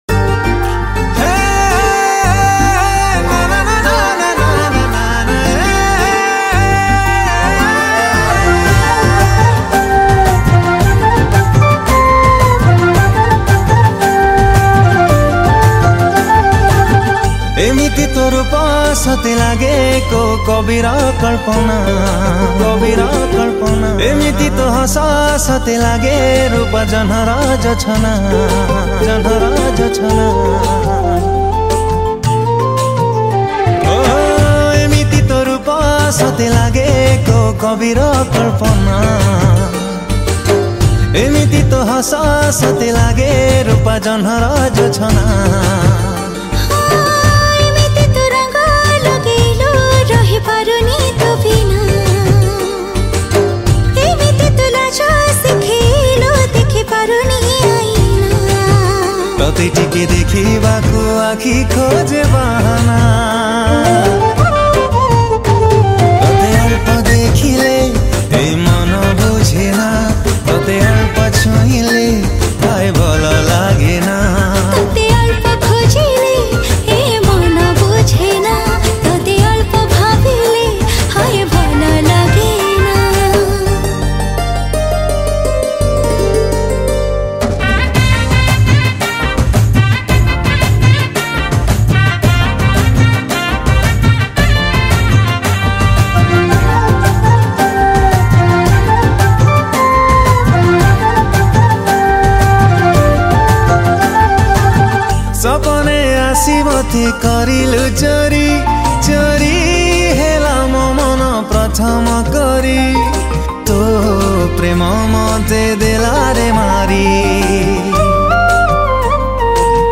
Keyboard